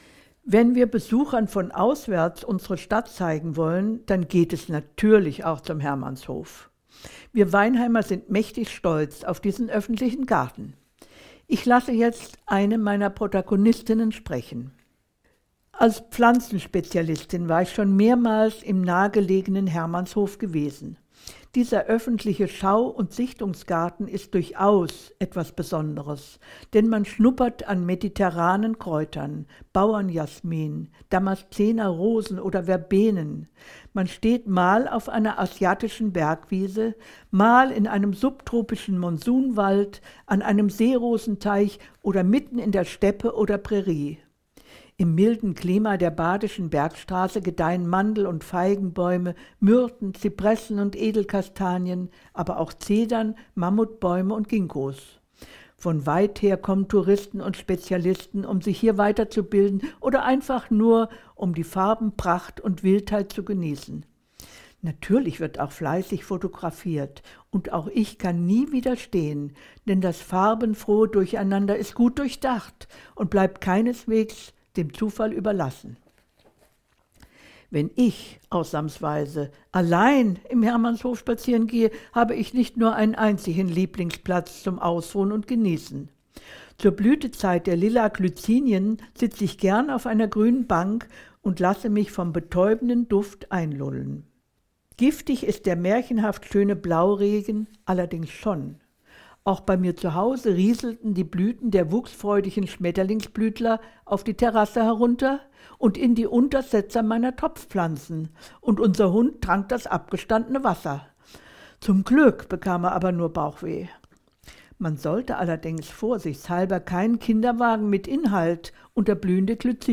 In ihrem ganz eigenen Stil und mit einem augenzwinkernd-subtilen Humor führt sie dann den Begleiter von Ort zu Ort.